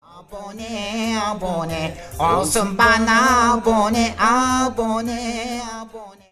Play the Abone abone yeni sark esshake sound button instantly. 6-second meme soundboard clip — free, in-browser, no signup, no download required.
A Turkish viral sound mixing repeated 'subscribe' phrases with mentions of new songs.